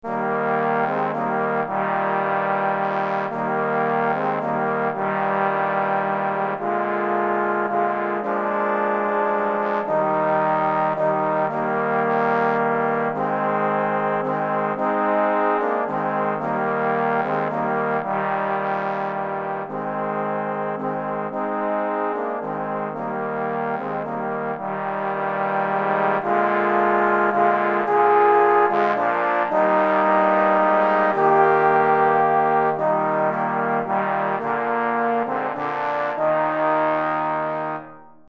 Recueil pour Trombone - 4 Trombones